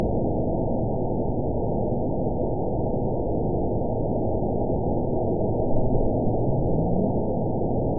event 911027 date 02/08/22 time 06:33:54 GMT (3 years, 3 months ago) score 9.04 location TSS-AB01 detected by nrw target species NRW annotations +NRW Spectrogram: Frequency (kHz) vs. Time (s) audio not available .wav